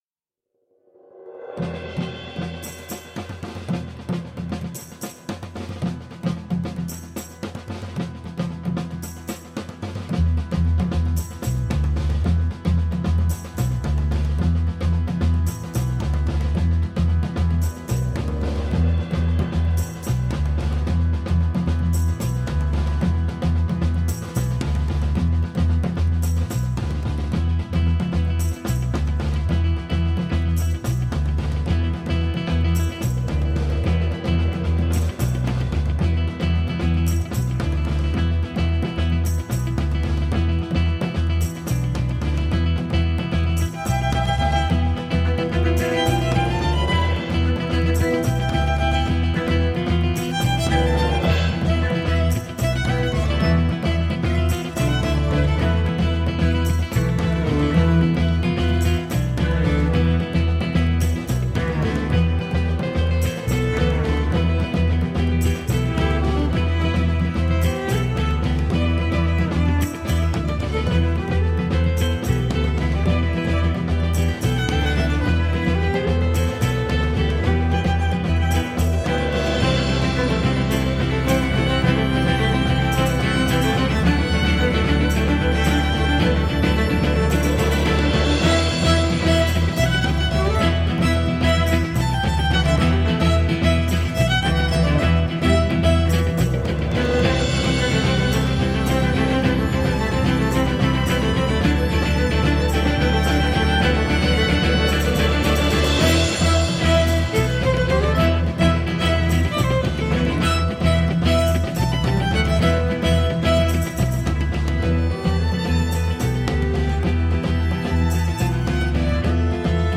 Early music meets global folk at the penguin café.